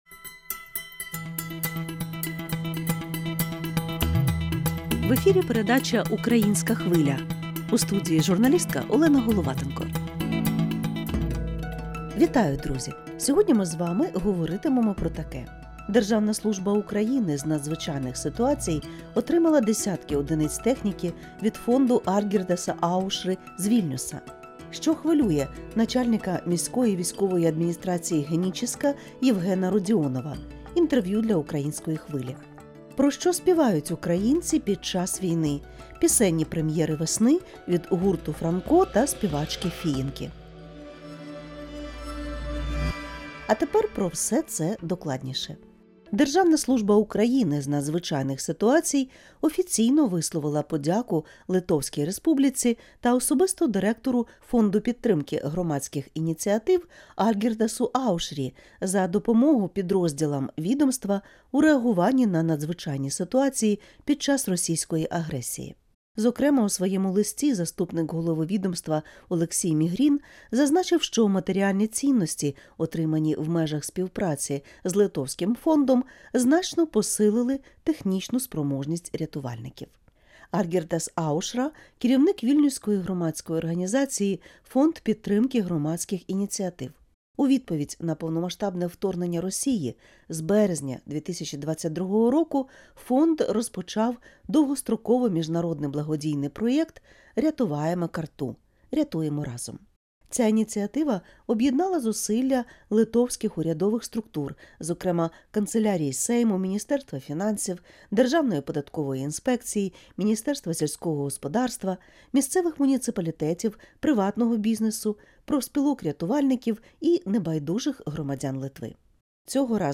Також у випуску — ексклюзивне інтерв'ю з начальником міської військової адміністрації Генічеська Євгенієм Родіоновим. Він розповість про досвід дистанційного управління громадою в окупації та збереження зв'язку із земляками-переселенцями.